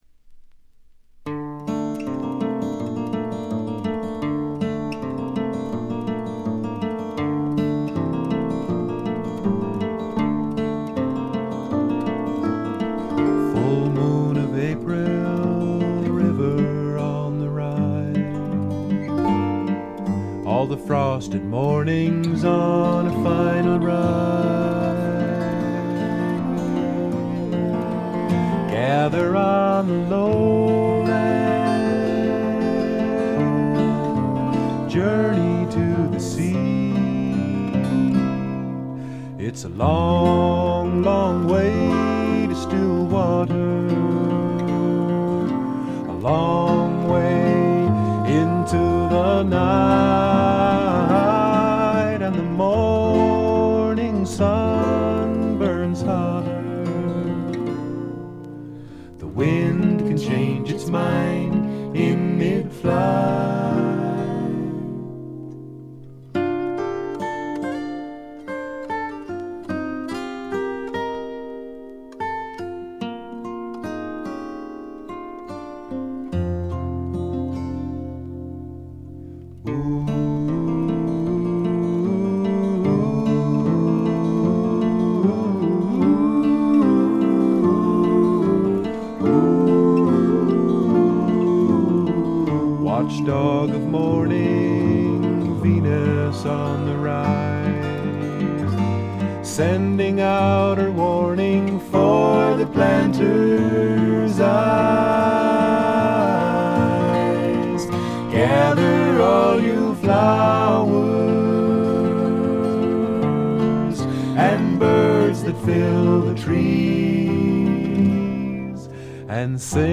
ほとんどノイズ感無し。
全体に静謐で、ジャケットのようにほの暗いモノクロームな世界。
試聴曲は現品からの取り込み音源です。
Vocals, Guitars, Harmonica